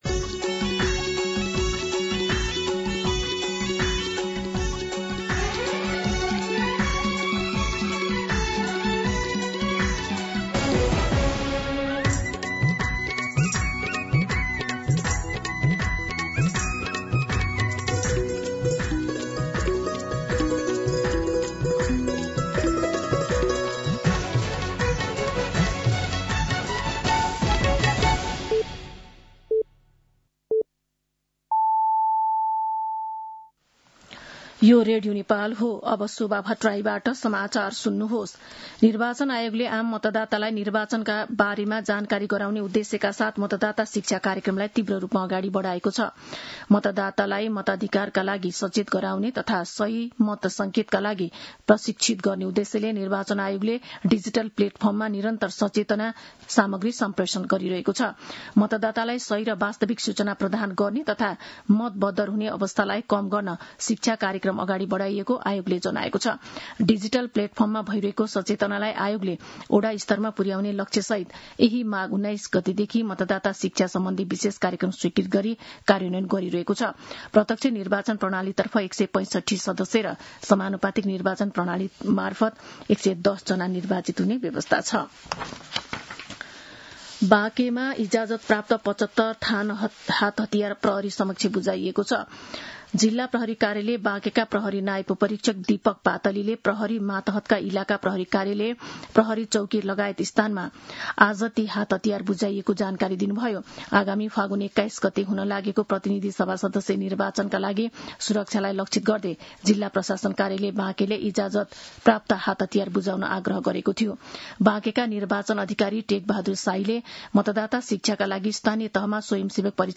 मध्यान्ह १२ बजेको नेपाली समाचार : २६ माघ , २०८२